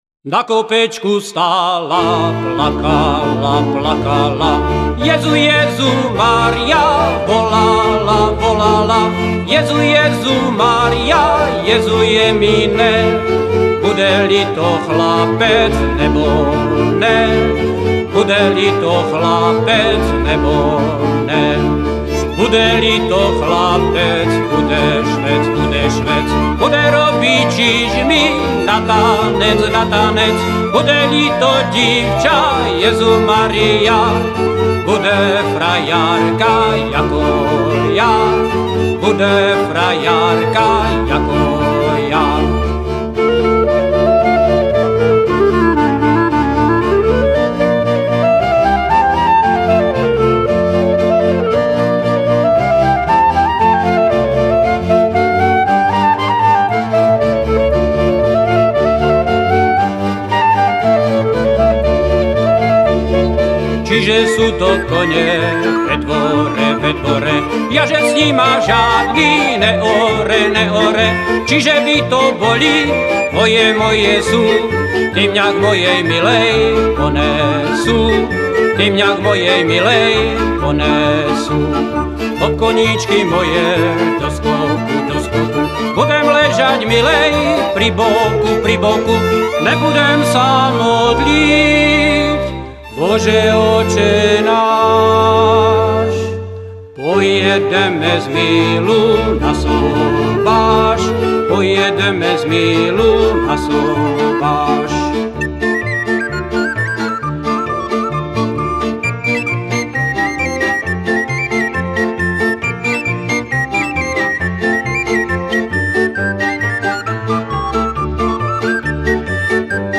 Pozdravy přátel MKS Adamov: CIMBALOVÁ SKUPINA MAJERÁN | MKS Adamov
Cimbálovka Majerán